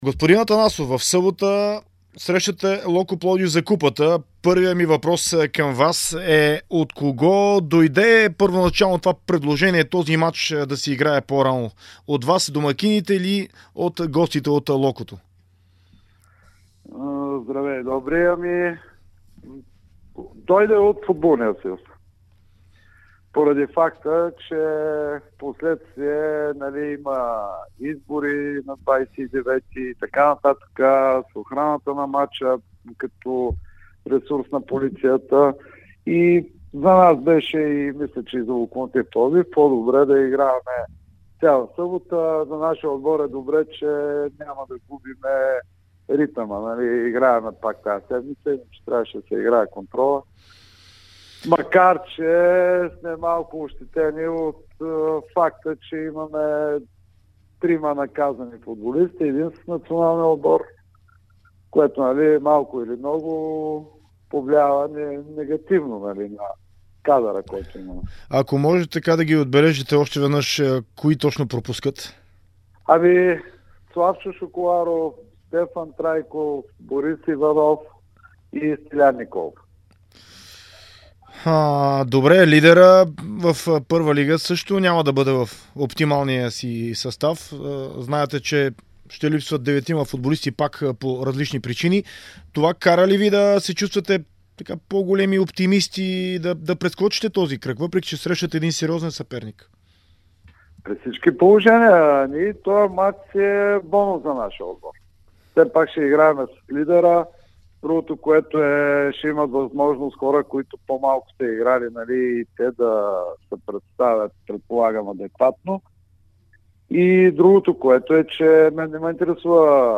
говори в интервю за Дарик радио и dsport за предстоящото домакинство за купата срещу Локомотив Пд.